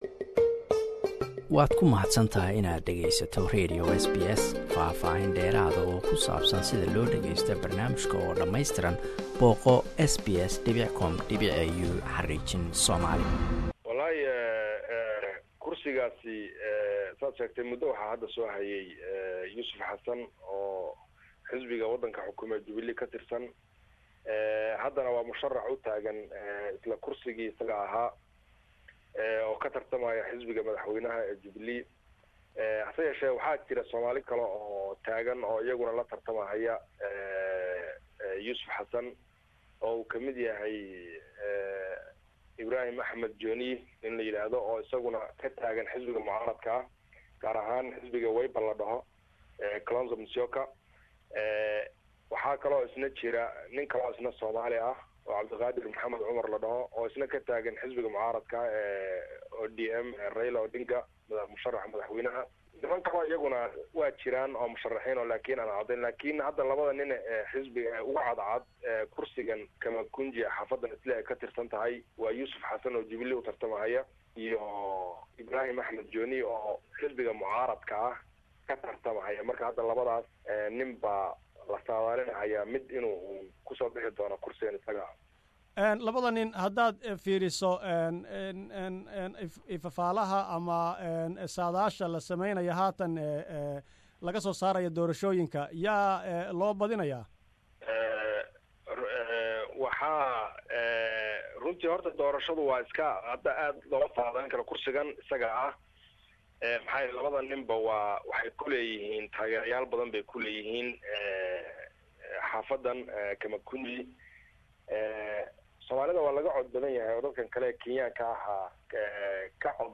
iyo waliba dhanka Garissa .waraysi